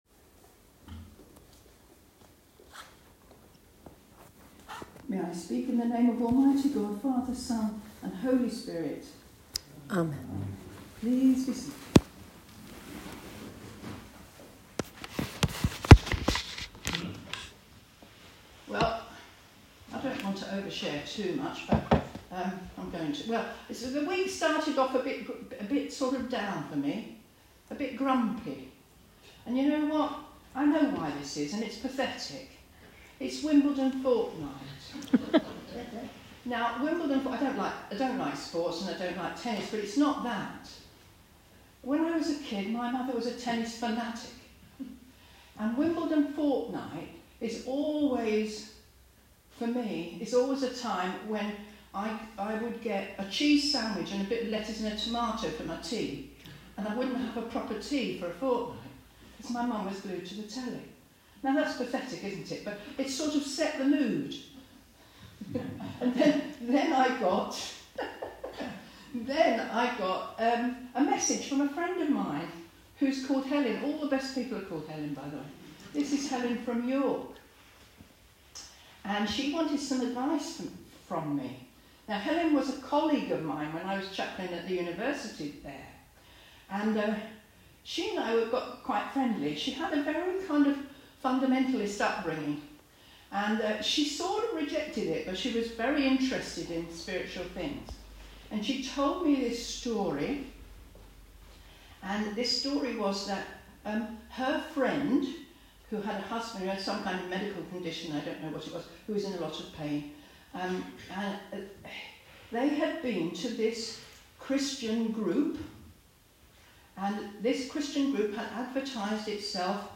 The following is the sermon